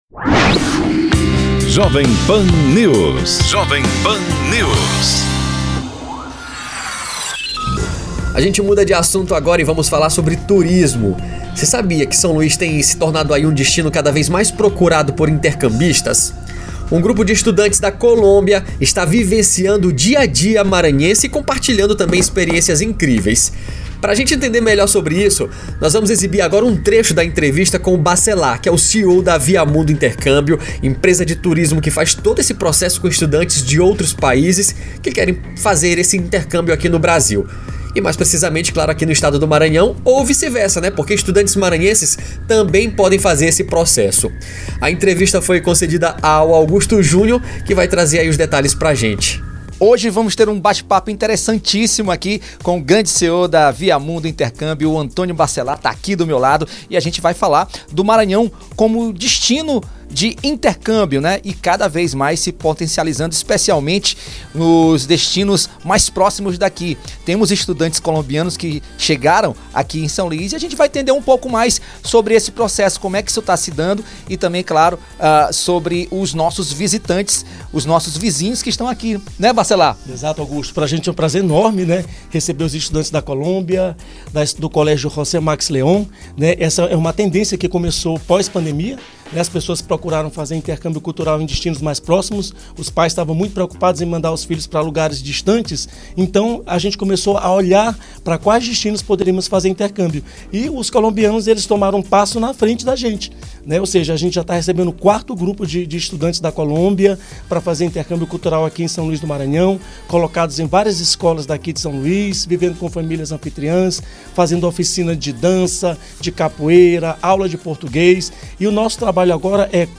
Entrevista à Rádio Jovem Pan (Aúdio: reprodução/ Pan News/ Rádio Jovem Pan FM)